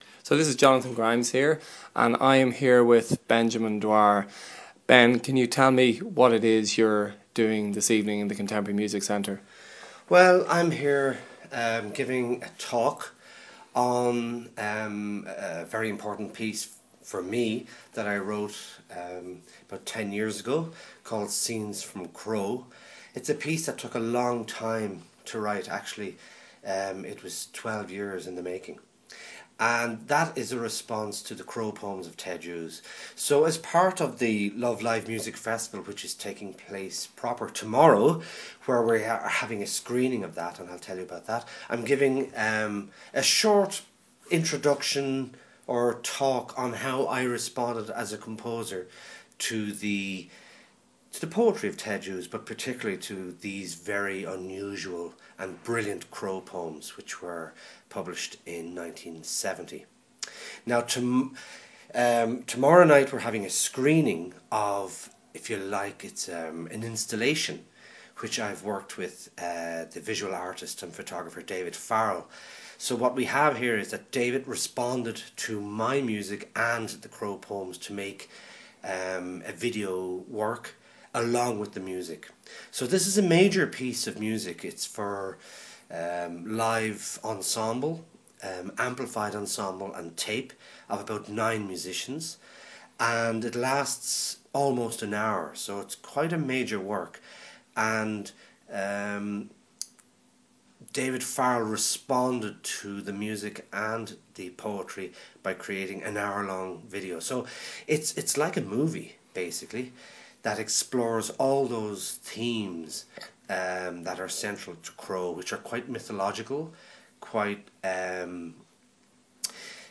Talking to composer